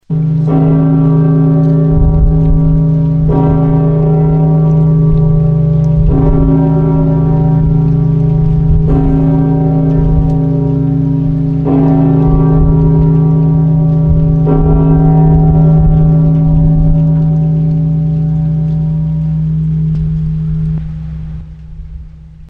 Звуки колокола
Звук Царь-колокола